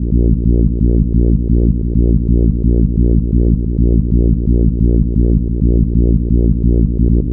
Index of /90_sSampleCDs/Club_Techno/Bass Loops
BASS_131_A#.wav